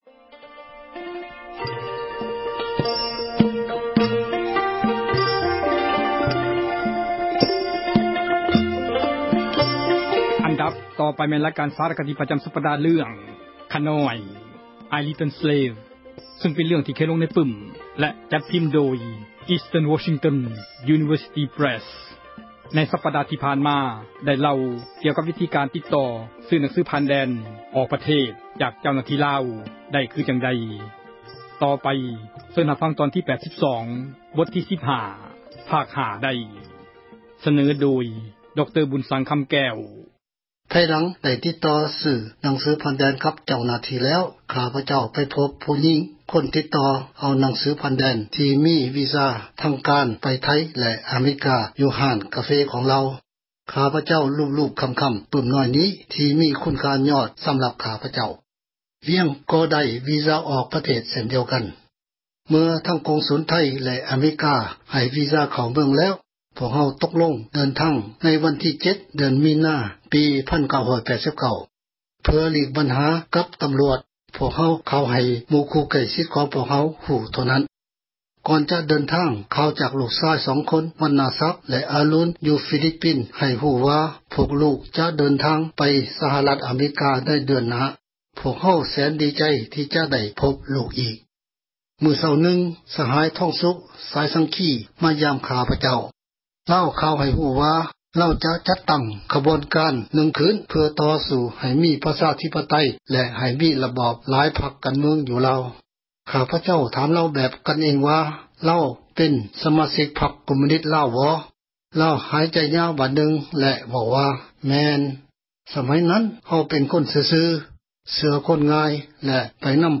ສາຣະຄະດີ ເຣື່ອງ ”ຂ້ານ້ອຍ"